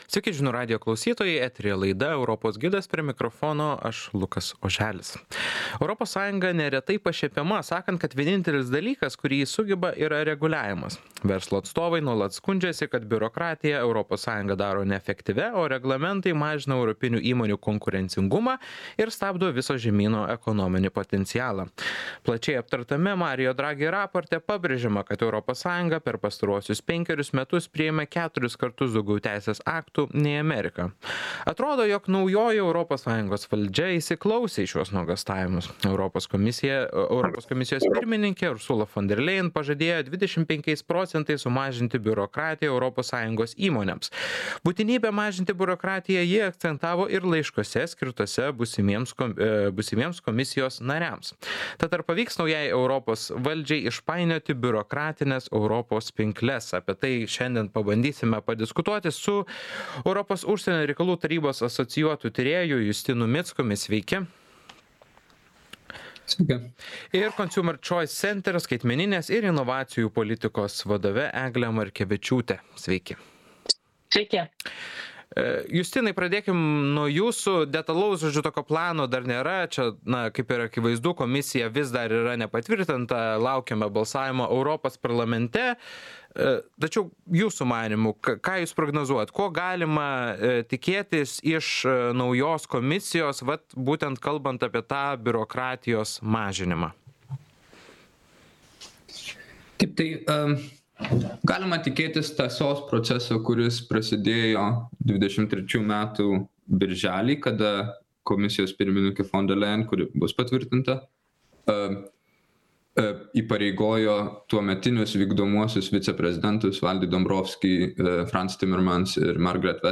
Apie tai Žinių radijo laidoje „Europos gidas" diskutavo